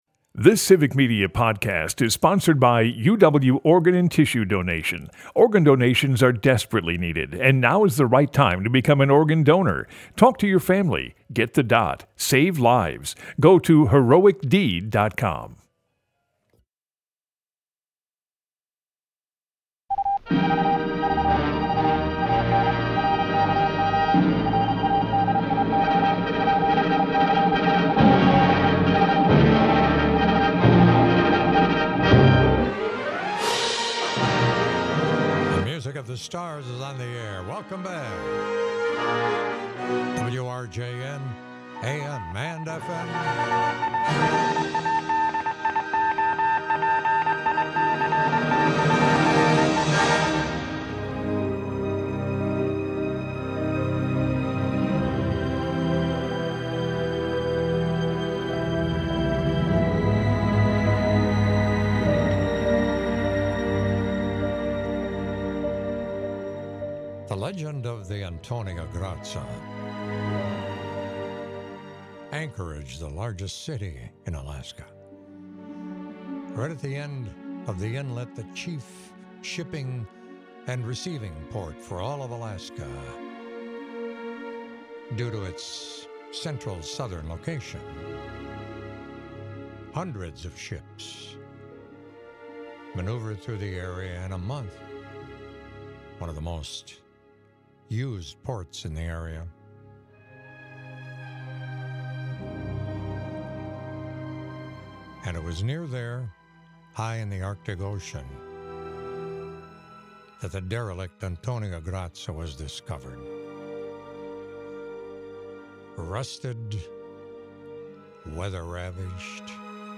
infinitely eclectic show